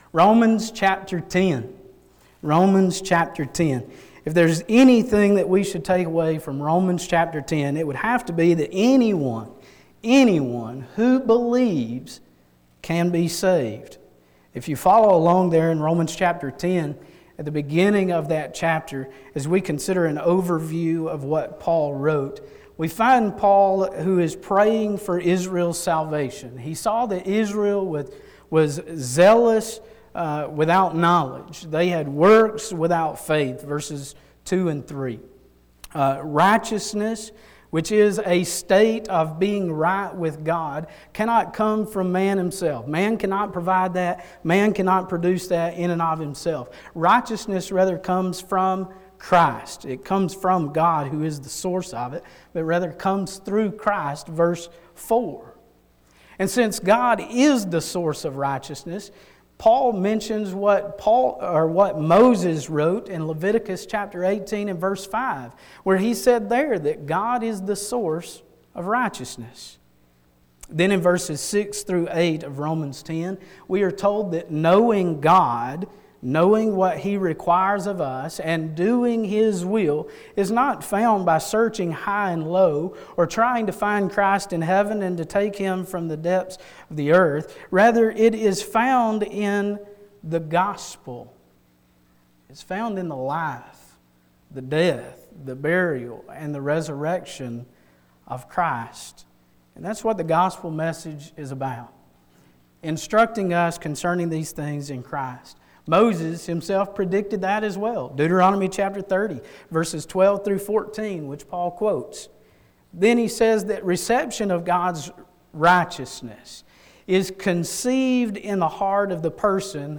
Ephesians 3:14-21 Service Type: Sunday Morning